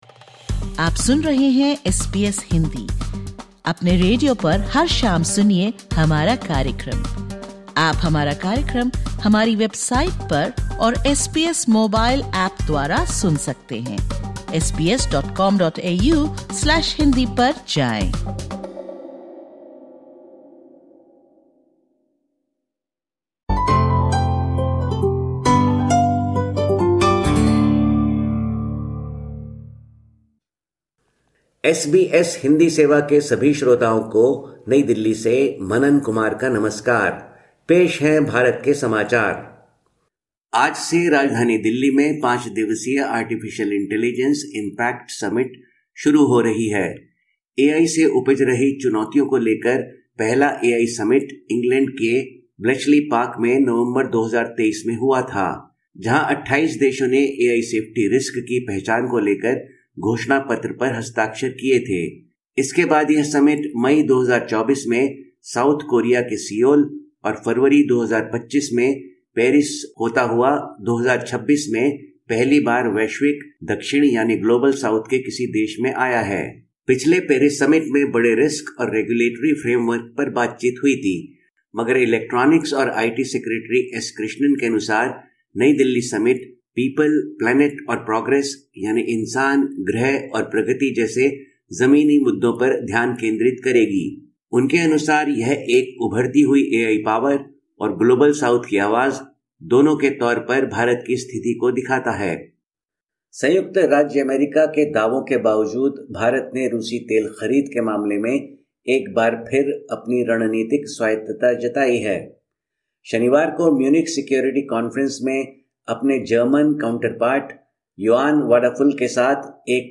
Listen to the latest SBS Hindi news from India. 16/02/2026